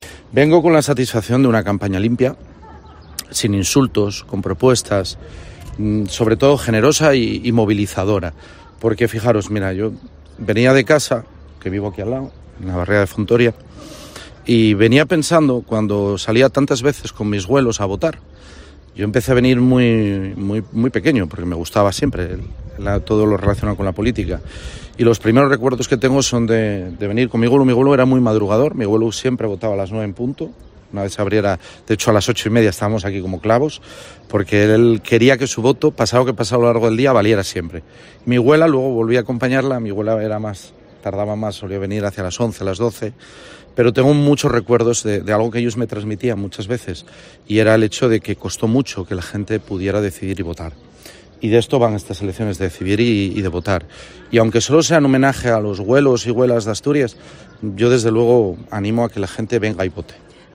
Así lo ha indicado en declaraciones a los medios a las puertas del Colegio Público Elena Sánchez Tamargo, de Laviana, donde ha ejercido su derecho al voto y donde ha recordado a sus abuelos.